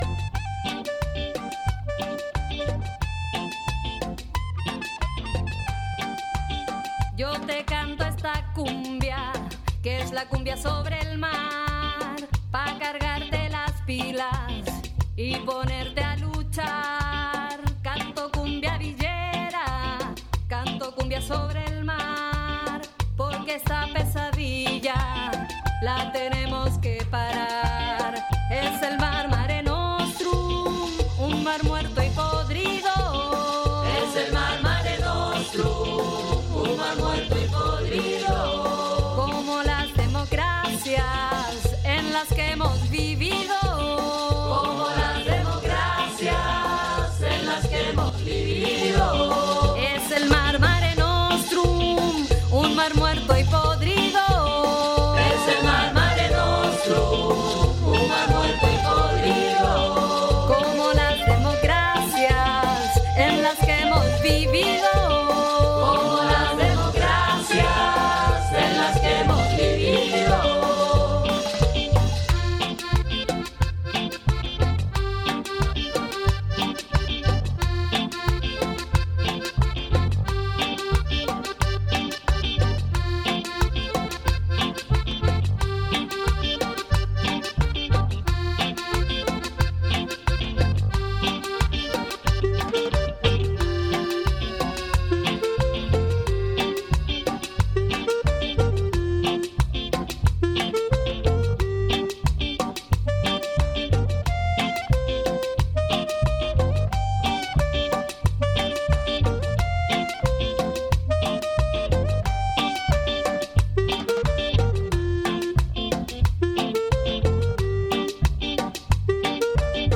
El pasado domingo 21 de noviembre acompañamos la jornada de inauguración de Rudas Resistencia Alimentaria, nuevas gestoras del bar del Casal de la Prosperitat.
En el reproductor escucháis un resumen de la jornada emitido ayer 27 de noviembre desde CorAnimal.